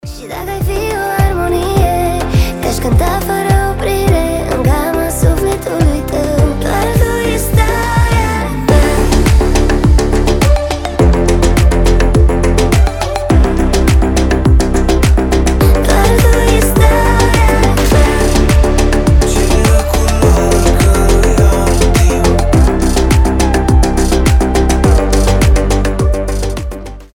поп
красивые
дуэт
басы